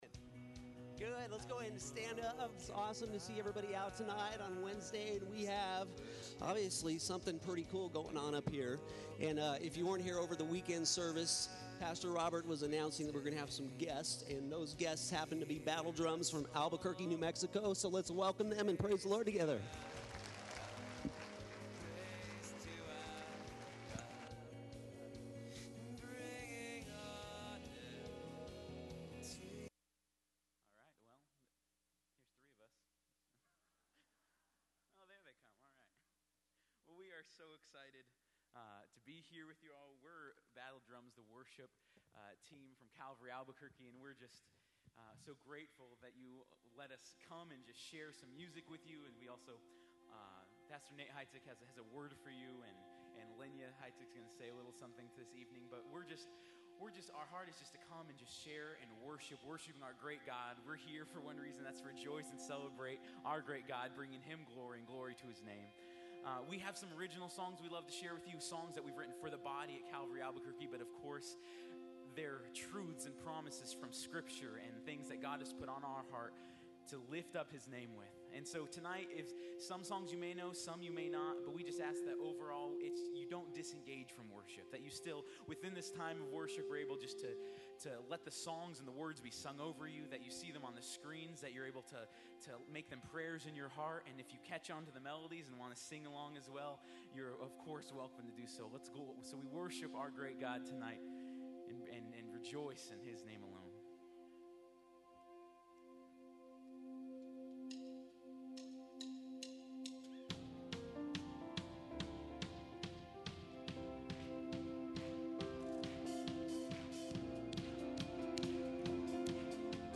Battle Drums Concert
give a special message